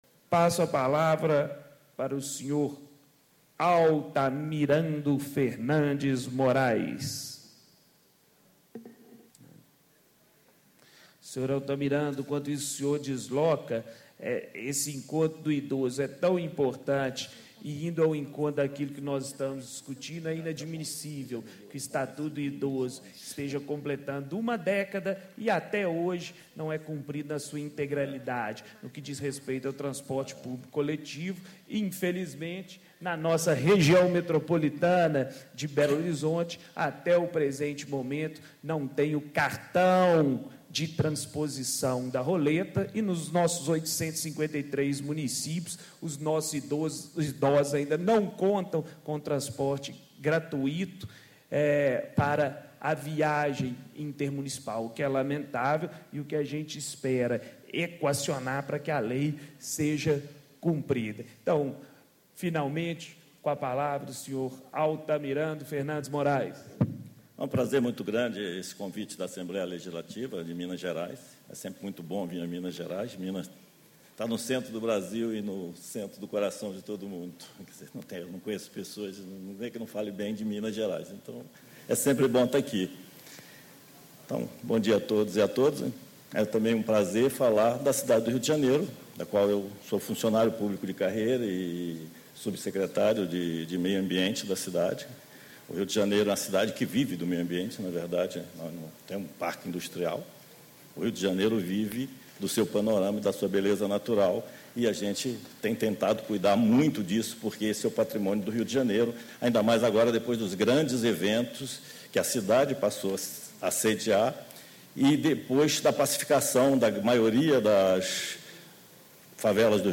Palestra: Mobilidade Urbana no Brasil: Desafios e Perspectivas - Altamirando Fernandes Moraes -Subsecretário de Meio Ambiente e presidente do Grupo de Trabalho de Planejamento Cicloviário da cidade do Rio de Janeiro
Encontro Estadual do Fórum Técnico Mobilidade Urbana - Construindo Cidades Inteligentes